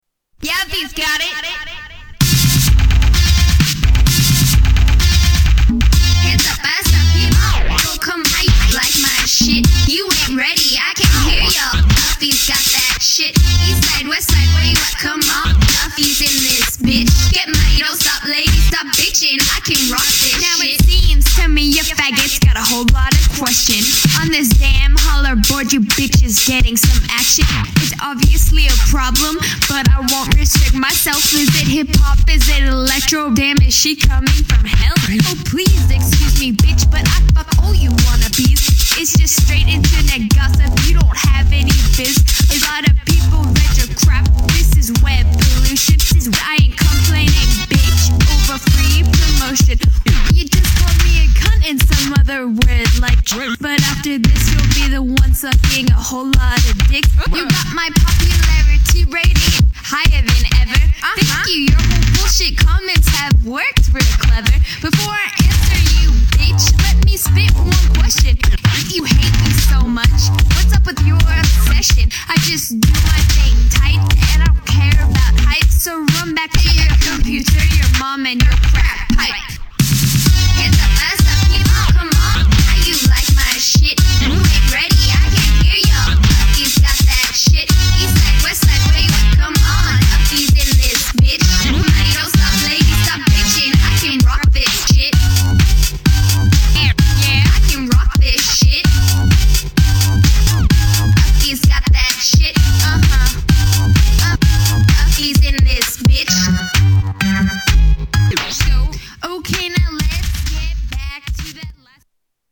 Electro Rap